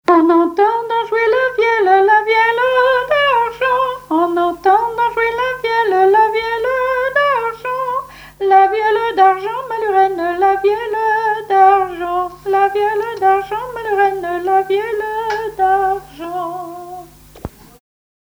vielleux, vielle à roue
Genre laisse
Pièce musicale inédite